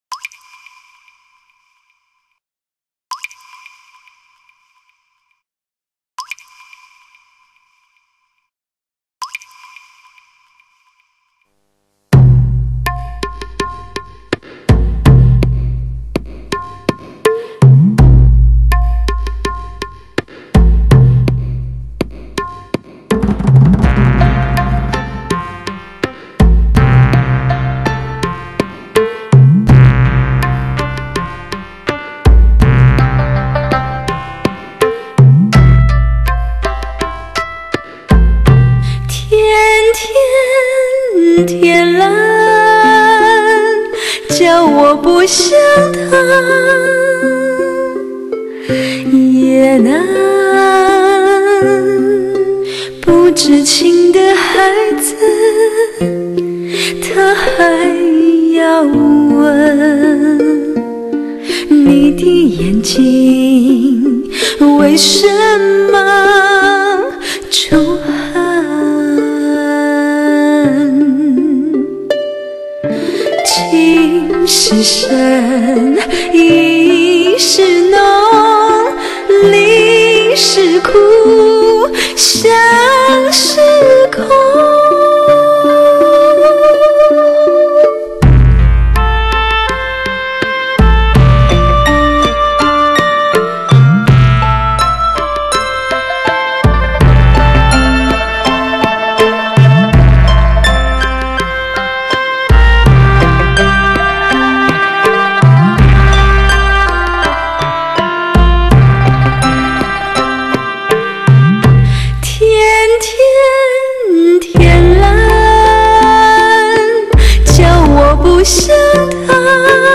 专辑类型：dts CD